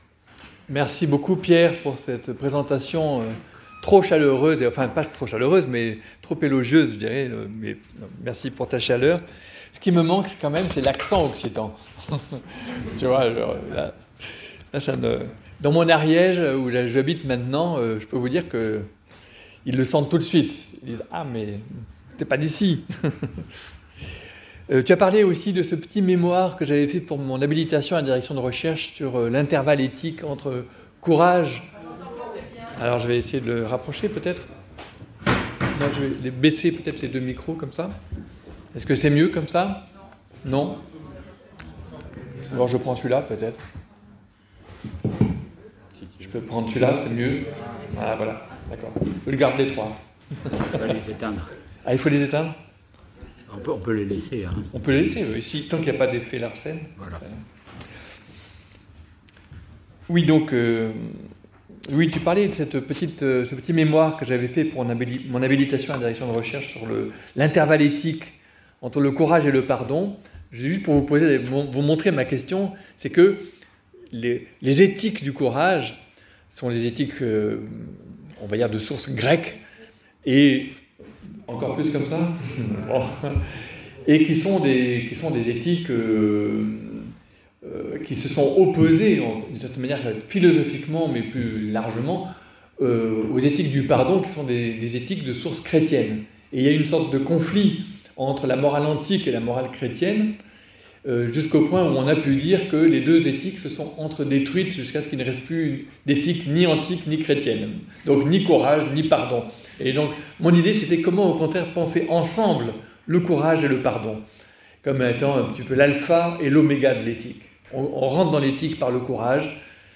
Conférence de Olivier Abel